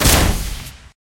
添加热诱弹音效和HUD提示
decoy_fire.ogg